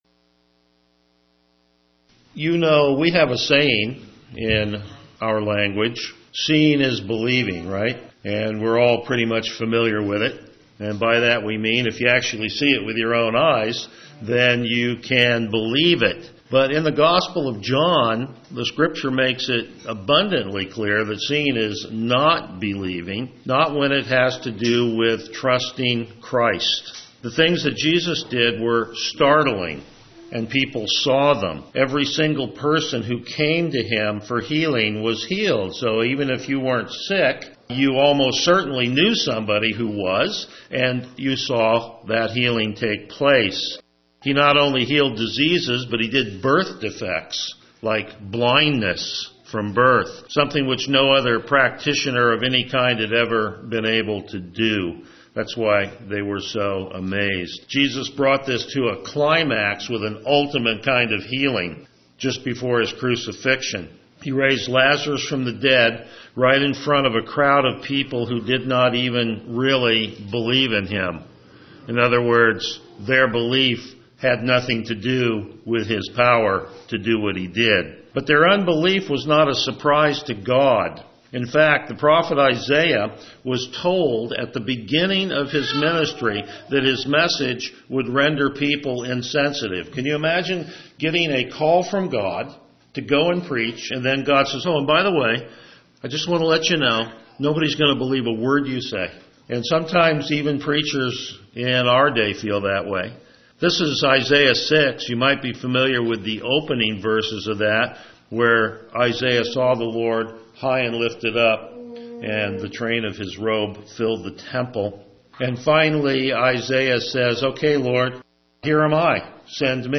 Passage: John 12:37-43 Service Type: Morning Worship